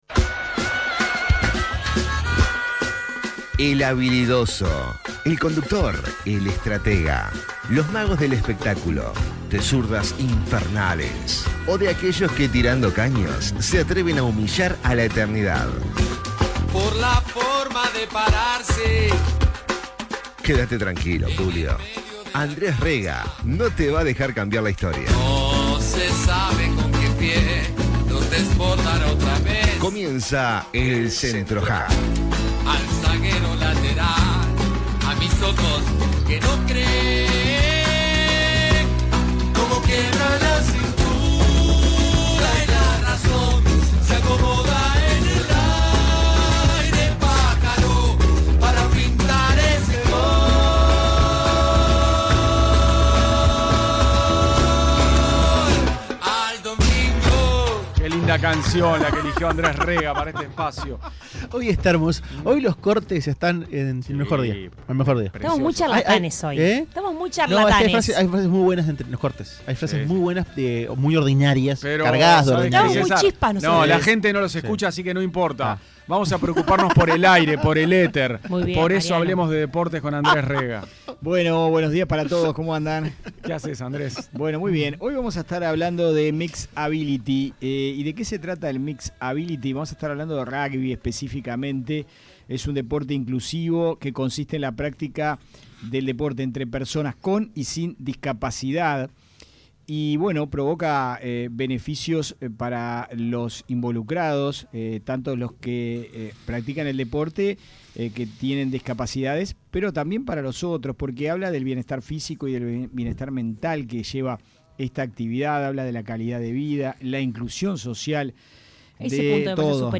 Vamos a escuchar los testimonios